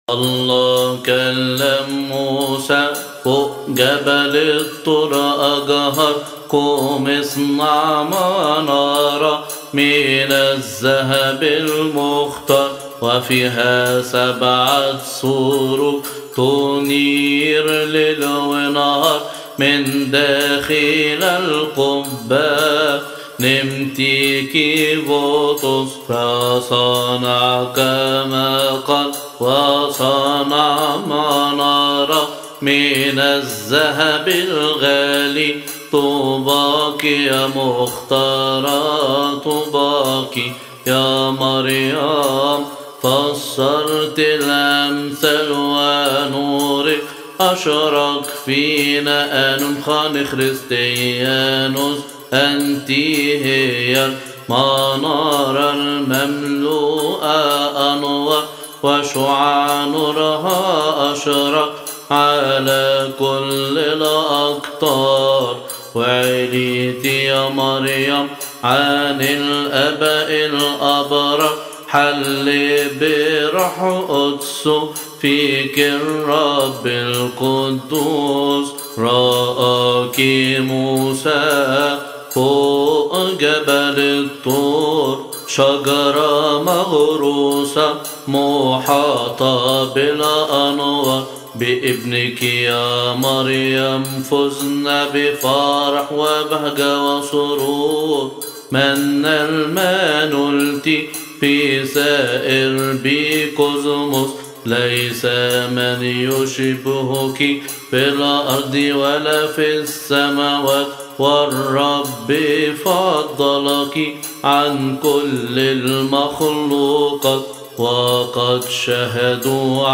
التفسير الخامس العربي لثيؤطوكية يوم الاحد يقال في تسبحة نصف الليل بشهر كيهك.